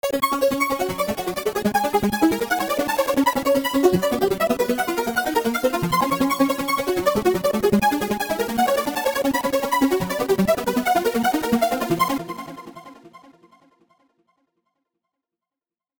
short pangram tunes. the second one is older and most of the notes are bunched toward the end, so it’s not as elegant.